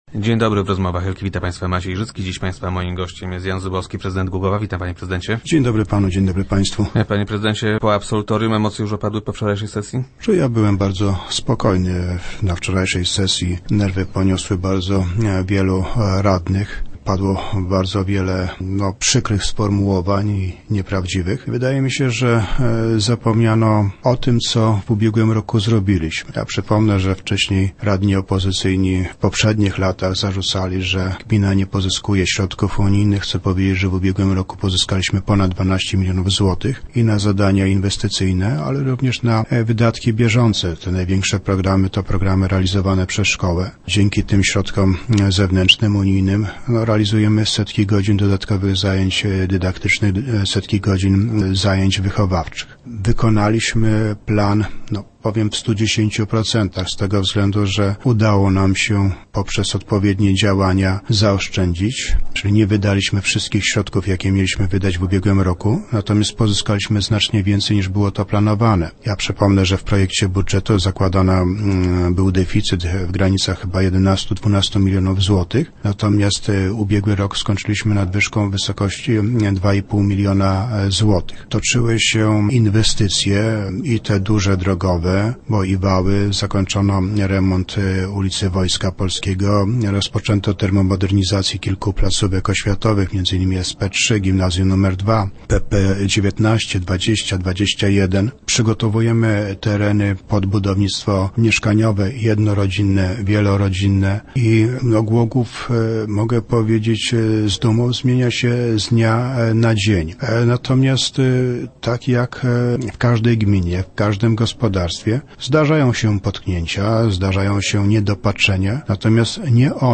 Opozycja jednak miała wiele zastrzeżeń do jego pracy. - Padło podczas sesji wiele przykrych i nieprawdziwych sformułowań – twierdzi prezydent Jan Zubowski, który był gościem Rozmów Elki.